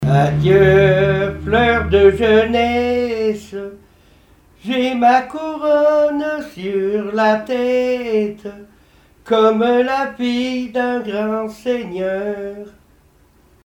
chansons et témoignages parlés
Pièce musicale inédite